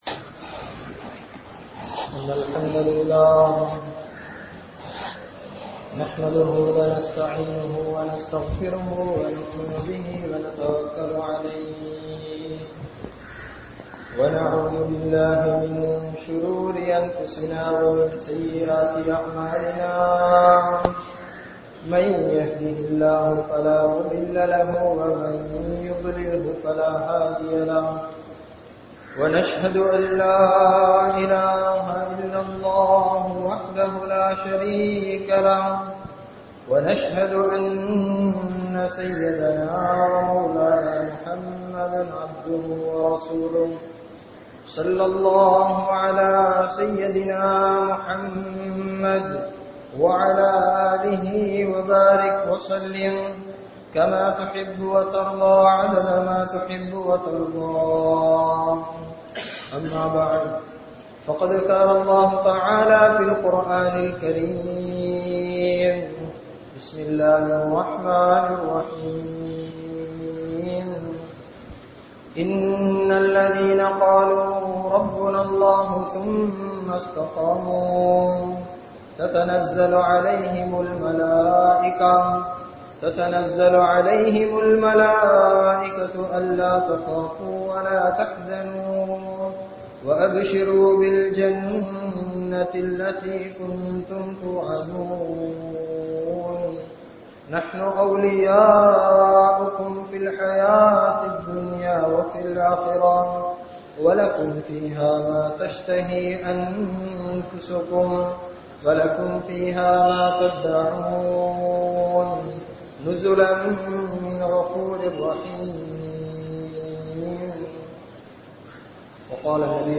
Miruthuvaaha Nadavungal (மிருதுவாக நடவுங்கள்) | Audio Bayans | All Ceylon Muslim Youth Community | Addalaichenai